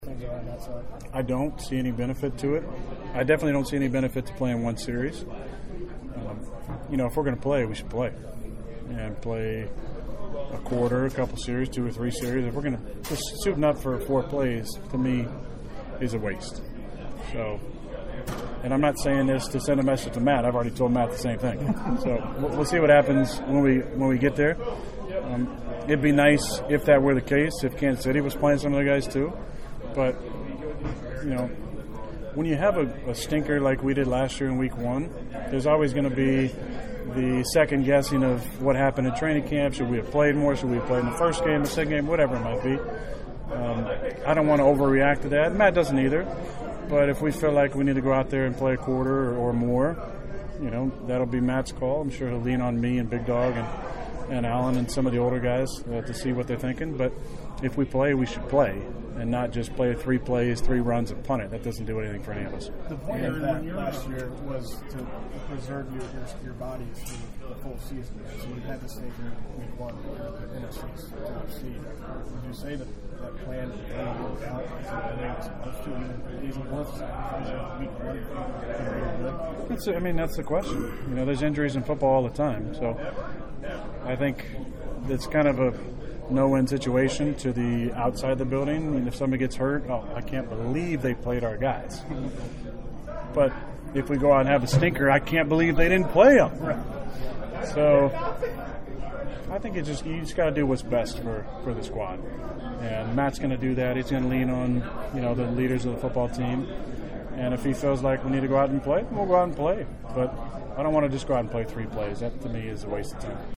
Even though he didn’t practice, Rodgers held court for his weekly chat with the media.